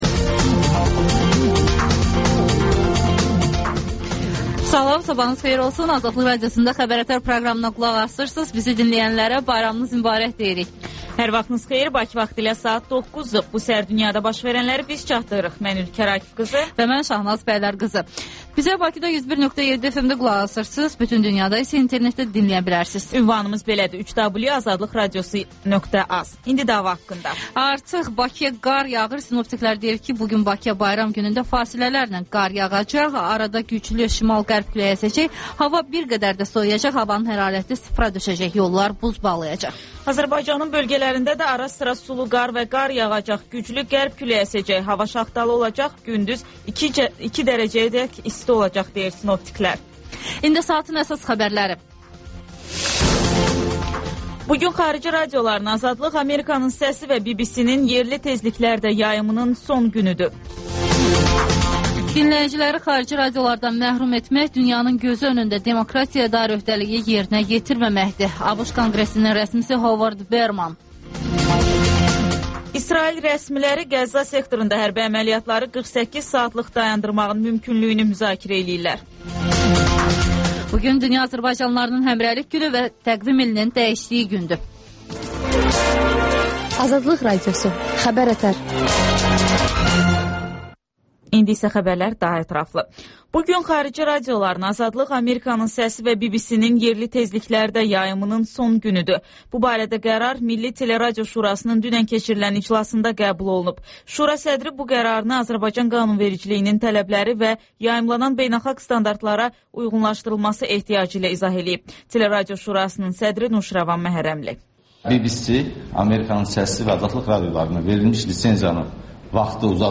Xəbər-ətər və XÜSUSİ REPORTAJ